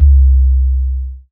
Urban Sub 01.wav